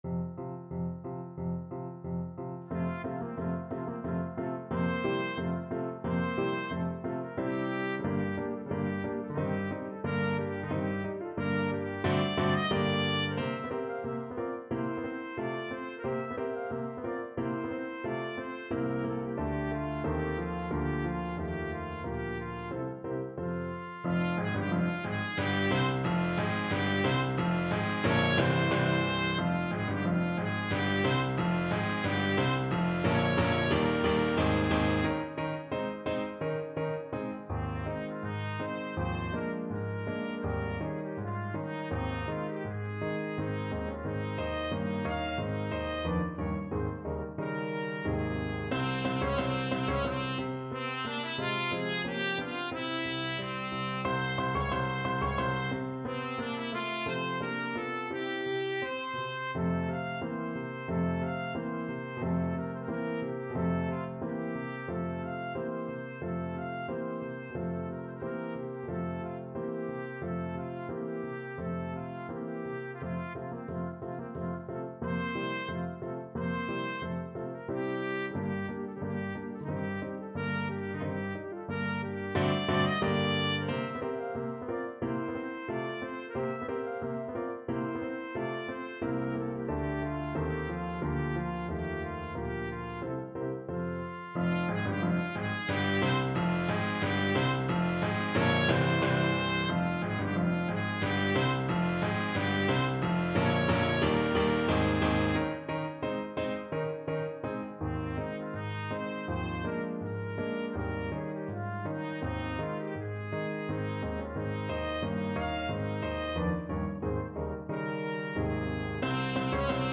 2/4 (View more 2/4 Music)
~ = 100 Allegretto moderato =90
Classical (View more Classical Trumpet Music)